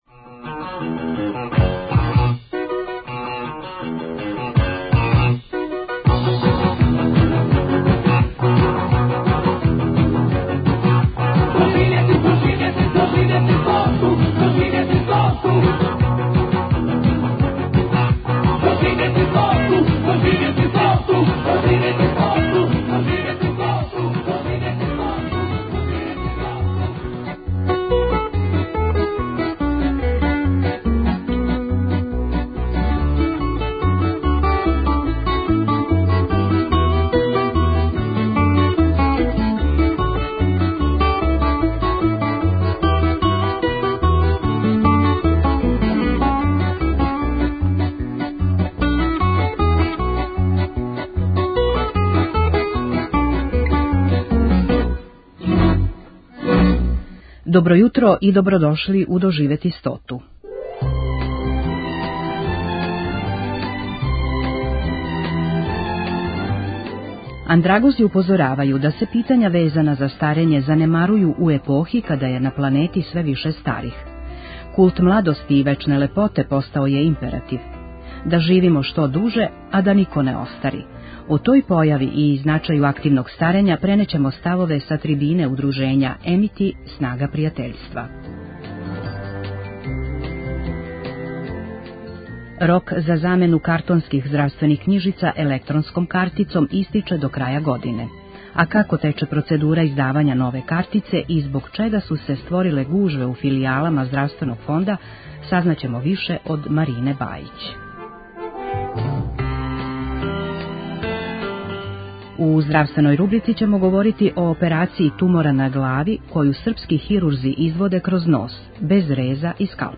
Андрагози упозоравају да се питања везана за старење прилично занемарују у епохи када је на планети све више старих. О тој појави и значају активног старења пренећемо ставове са трибине Удружења 'Amity - снага пријатељства'.
Емисија "Доживети стоту" Првог програма Радио Београда већ двадесет четири године доноси интервјуе и репортаже посвећене старијој популацији.